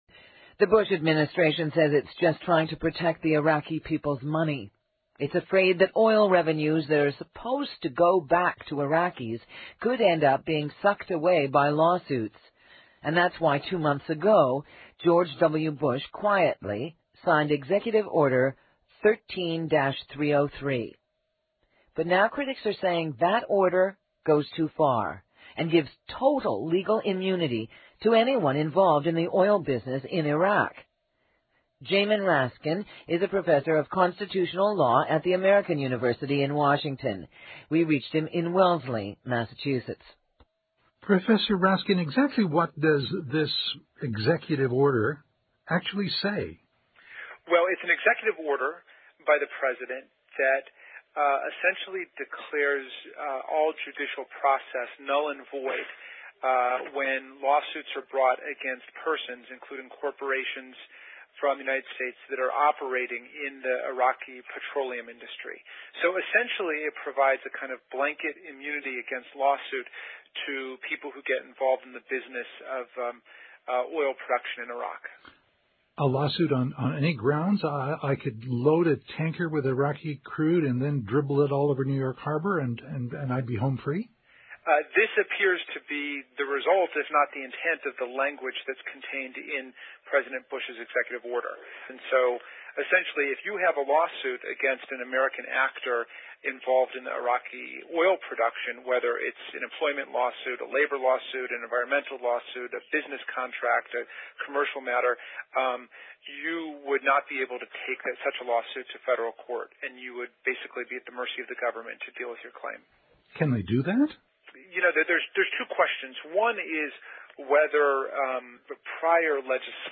IRAQI OIL IMMUNITY (8 min. CBC audio interview) and now "a law that gives away publicly owned minerals, contains no environmental standards, and maintains a 19th century land disposal ethic..."